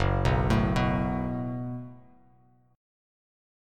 Gbmbb5 chord